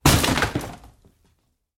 Звуки удара по столу
Звук мощного удара по столу, от которого стол развалился